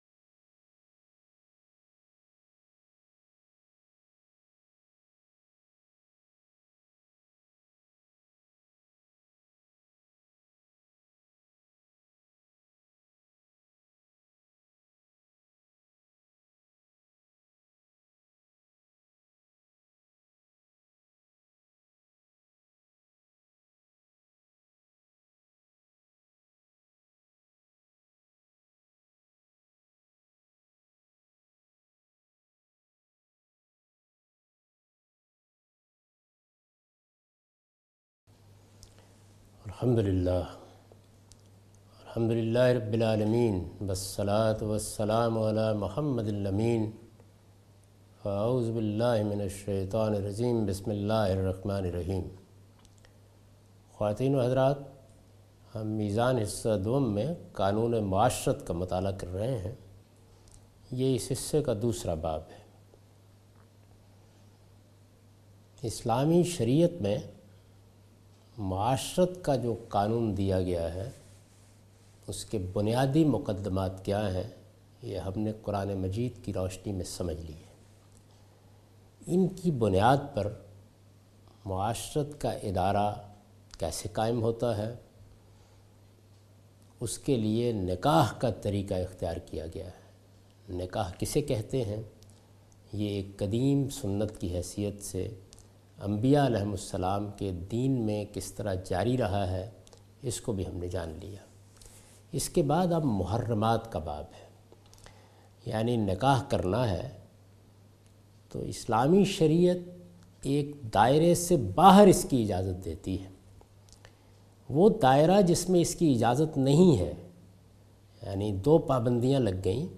A comprehensive course on Islam, wherein Javed Ahmad Ghamidi teaches his book ‘Meezan’.